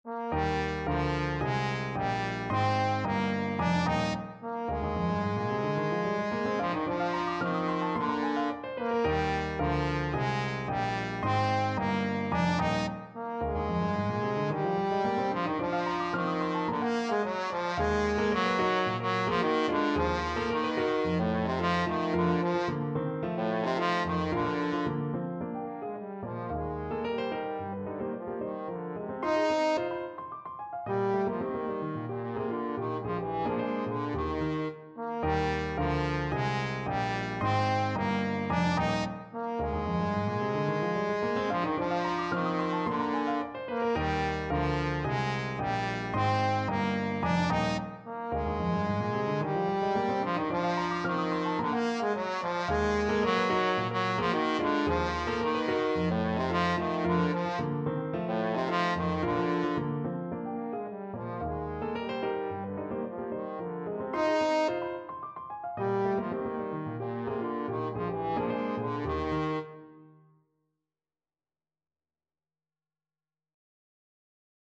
2/4 (View more 2/4 Music)
Classical (View more Classical Trombone Music)
Cuban